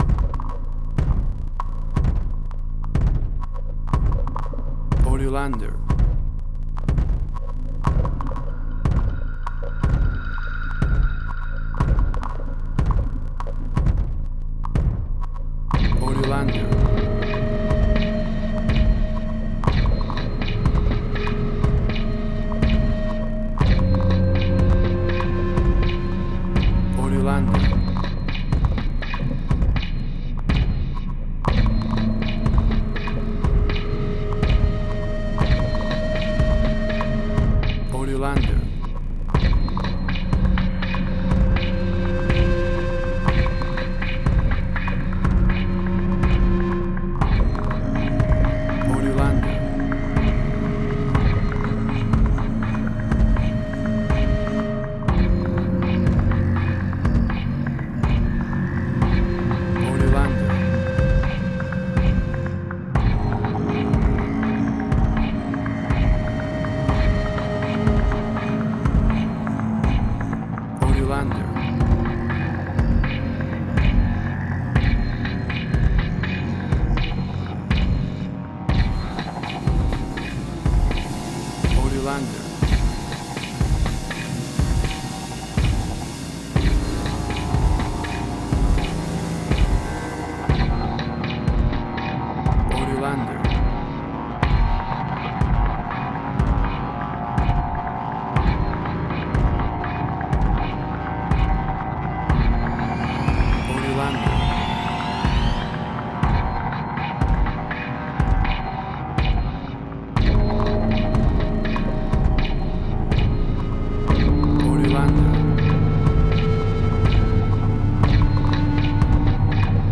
Suspense, Drama, Quirky, Emotional.
WAV Sample Rate: 16-Bit stereo, 44.1 kHz
Tempo (BPM): 122